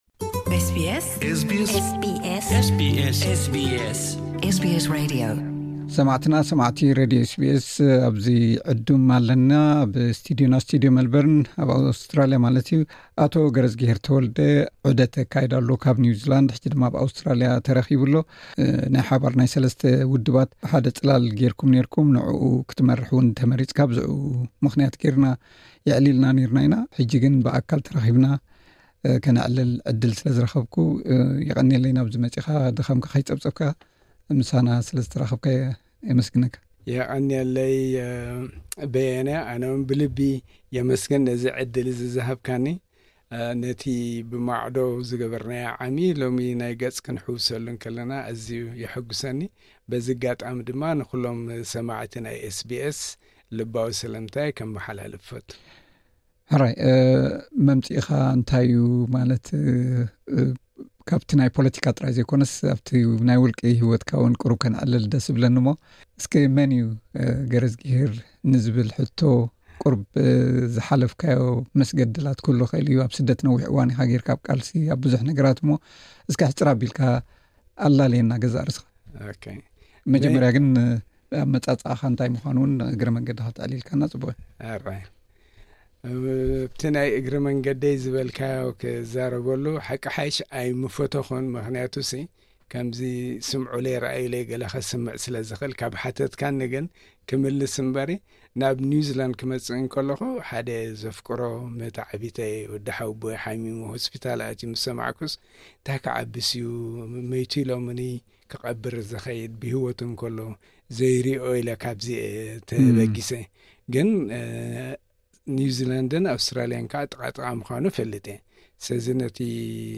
ብዛዕባ ተሞክሮ ህይወቱን ህሉው ኩነታት ፖሎቲካን ኣብ ስቱድዮ ኤስ ቢ ኤስ መልበርን ኣካፊሉ ኣሎ።